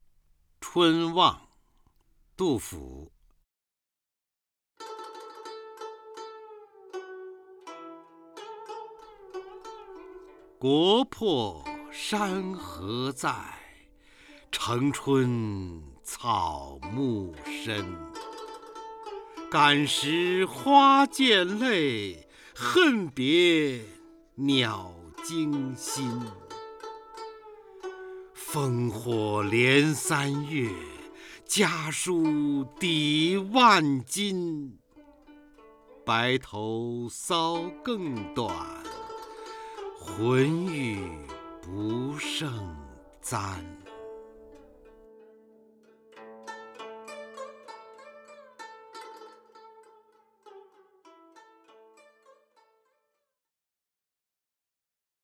方明朗诵：《春望》(（唐）杜甫) （唐）杜甫 名家朗诵欣赏方明 语文PLUS